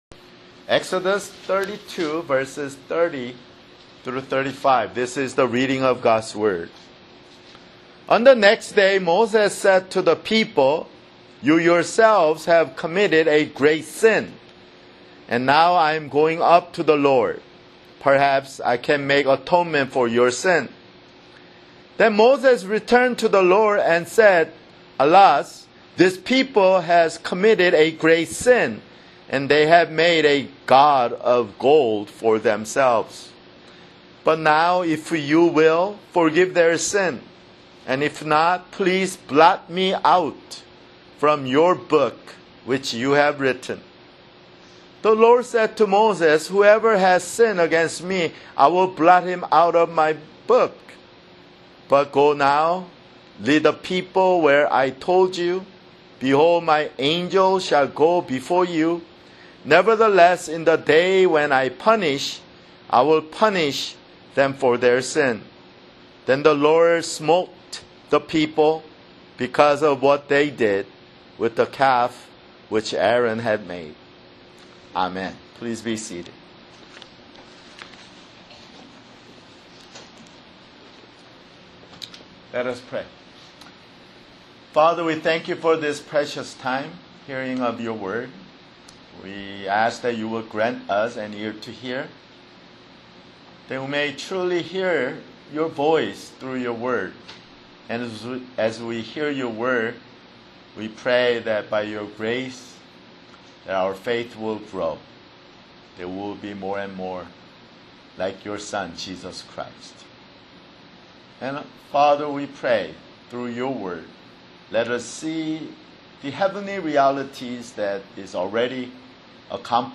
[Sermon] Exodus (95)